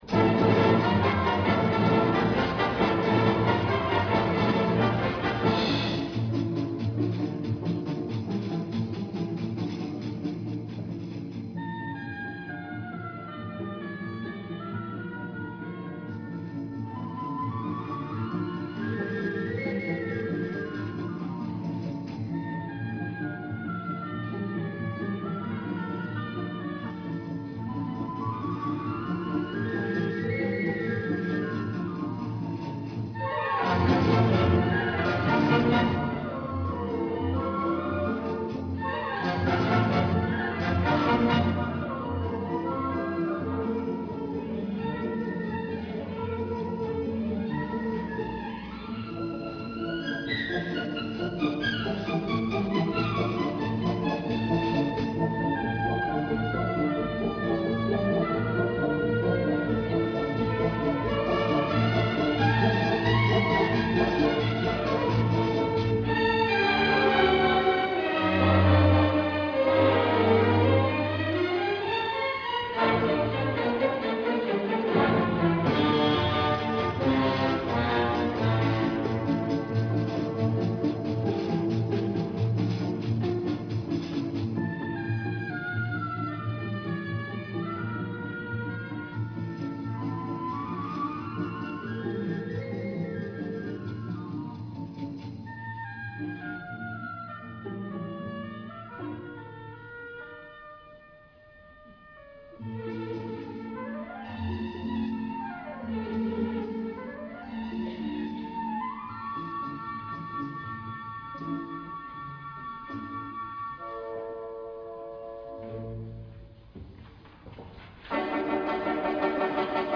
Baldani & Carreras Zürich-- 150 Jahre Züricher Oper, 1.Dezember 1984 Carmen, Akt 4, komplett 4.Akt